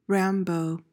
PRONUNCIATION: (RAM-bo) MEANING: noun: A violently aggressive person, especially one who disregards rules or authority.